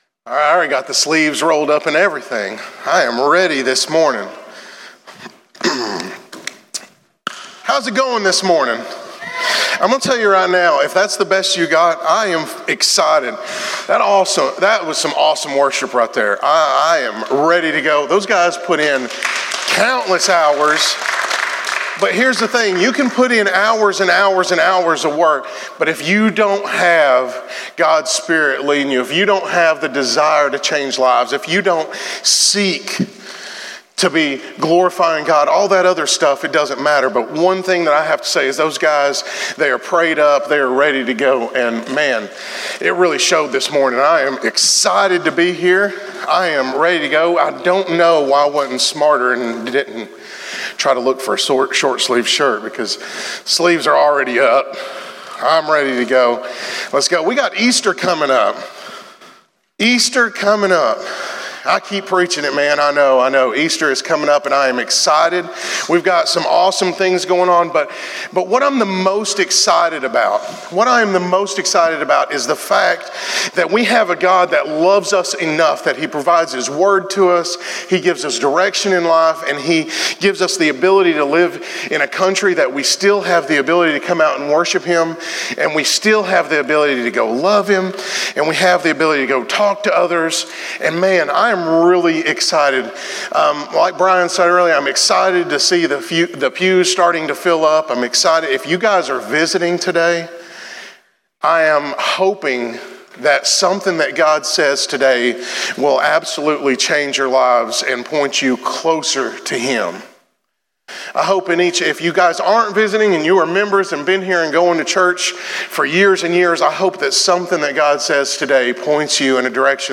In today's sermon, we talk about the dangers of going through the motions of being a follower of Christ, and the importance of having a real relationship with Jesus.